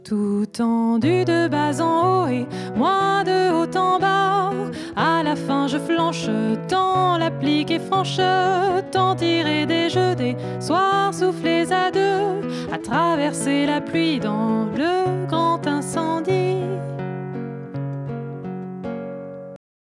Guitar + Voice
Dry: